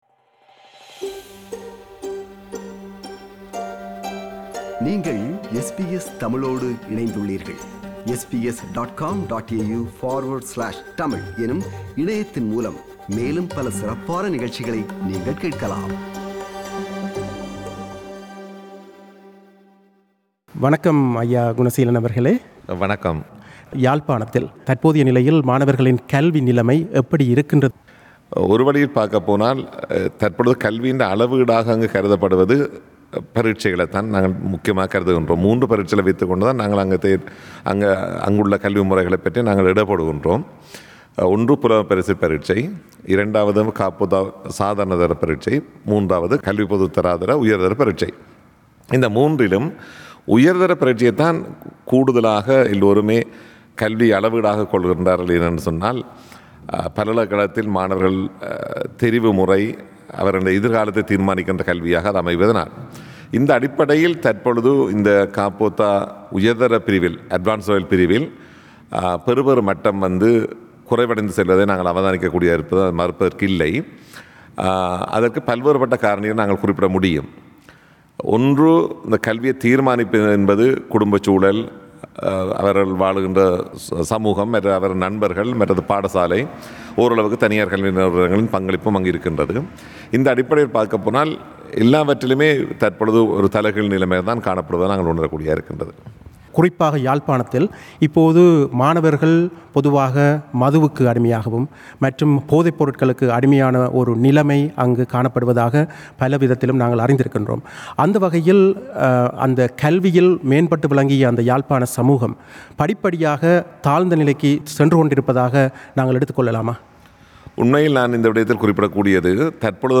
அப்போது சிட்னியில் அவரின் மாணவர்களுடனான சந்திப்பில் ஈடுபட்டிருந்த அவருடன் சந்தித்து உரையாடினார்